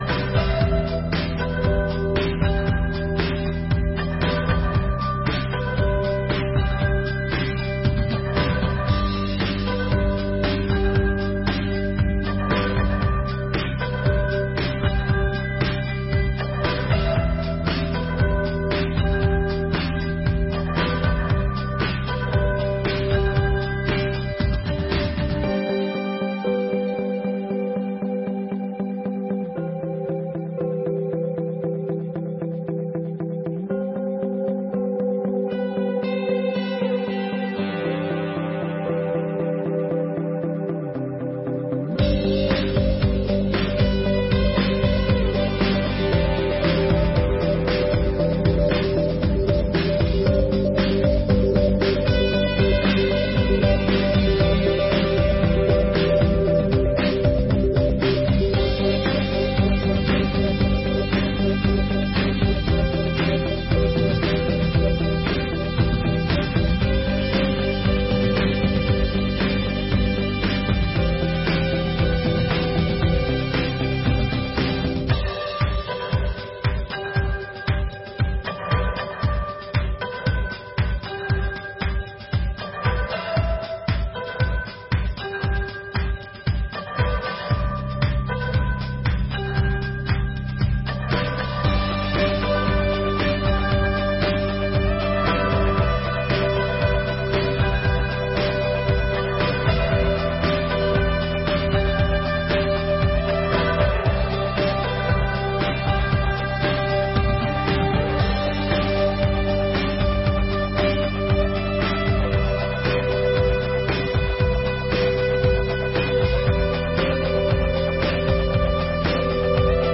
25ª Sessão Ordinária de 2022